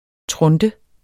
Udtale [ ˈtʁɔndə ]